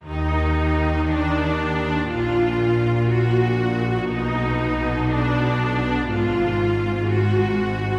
描述：管弦乐的弦乐演奏响亮而有力。
Tag: 120 bpm Cinematic Loops Strings Loops 1.35 MB wav Key : D